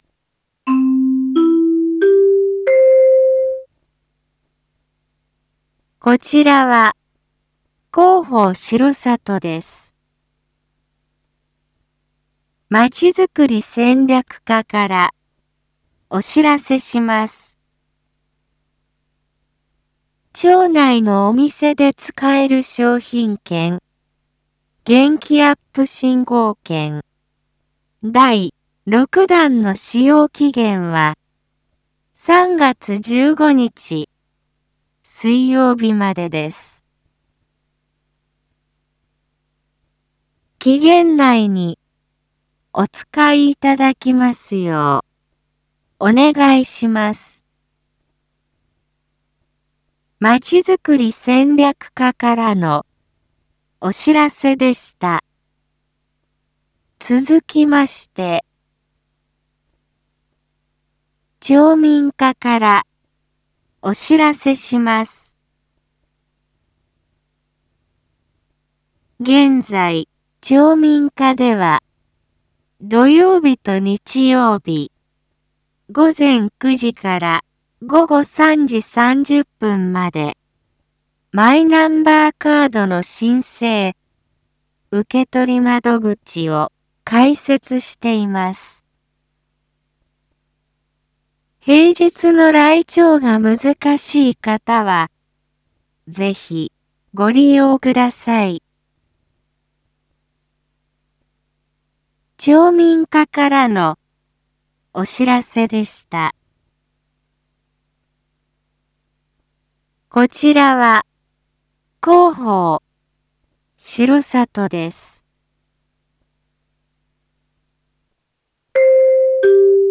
一般放送情報
Back Home 一般放送情報 音声放送 再生 一般放送情報 登録日時：2023-03-11 19:02:08 タイトル：R5.3.11 19時放送分 インフォメーション：こちらは、広報しろさとです。